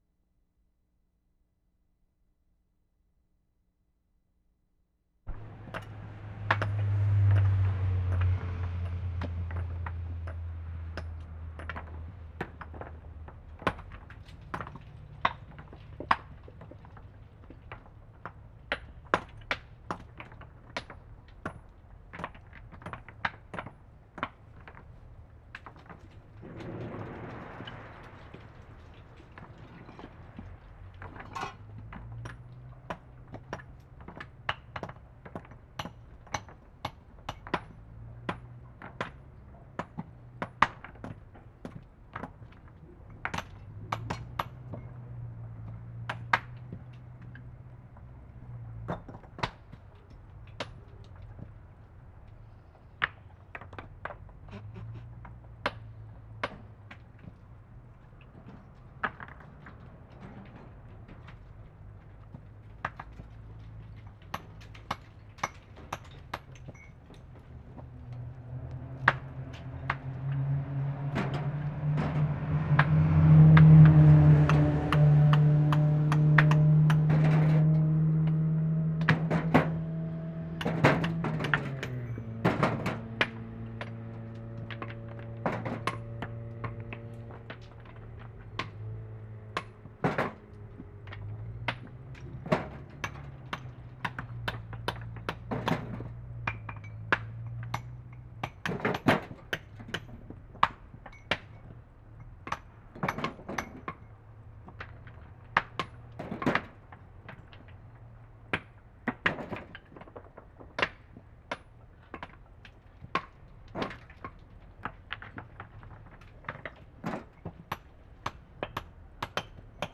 STONE CUTTERS (on road to Trento) chipping stone in quarry.
4. 3 men, 2 cutting stone and 1 pushing wheelbarrow.